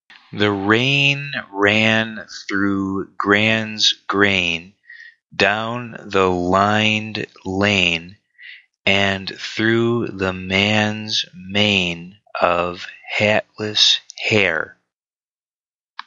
Again, each tongue twister comes with a model recording that can guide your pronunciation.
MODEL RECORDING